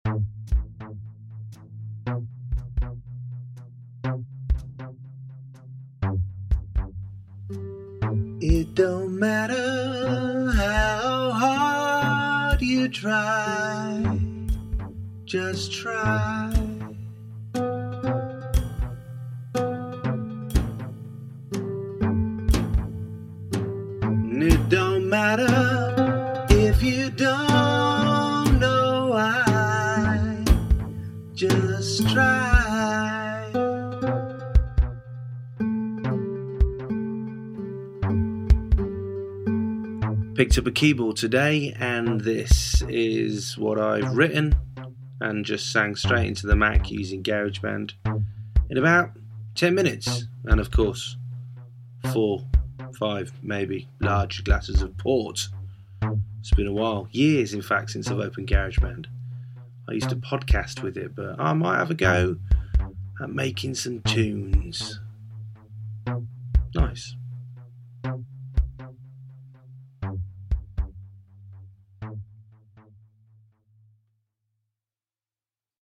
Just a test with a midi keyboard I picked up today.
I took 10 mins with the keyboard plugged into Garage Band and sang into my Mac's internal mic.